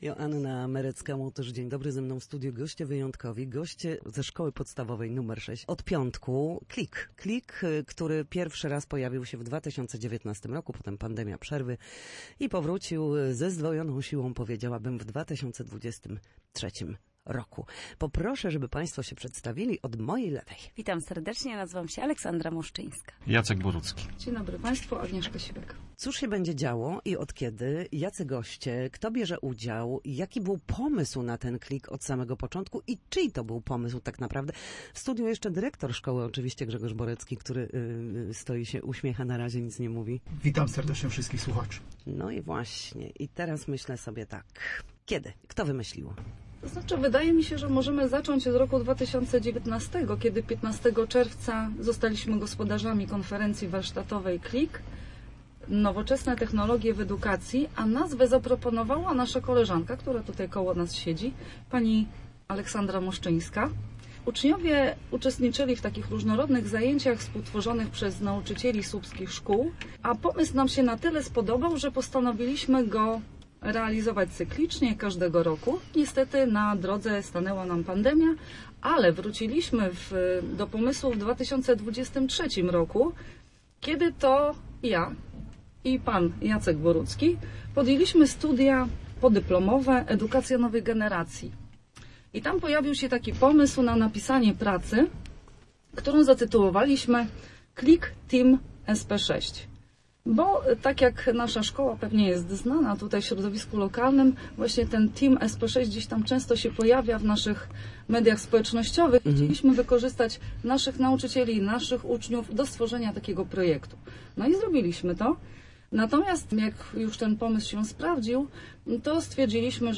KLIK 2025 to warsztaty dla ciekawskich w Szkole Podstawowej nr 6 w Słupsku. Gośćmi naszego studia byli nauczyciele i współorganizatorzy